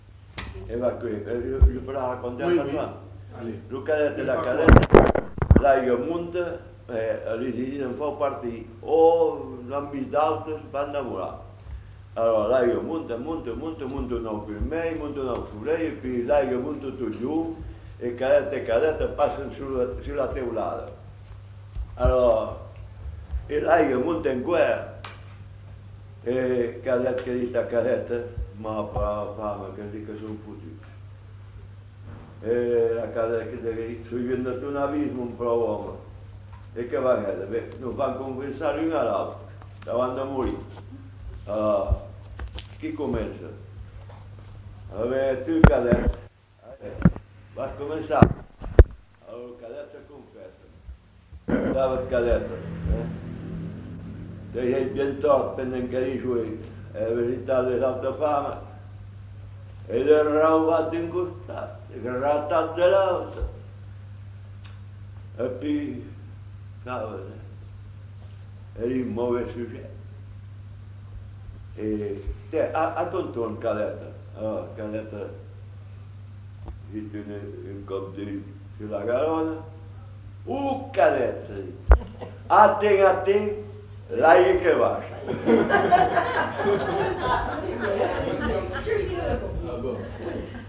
Lieu : Castets-en-Dorthe
Genre : conte-légende-récit
Effectif : 1
Type de voix : voix d'homme
Production du son : parlé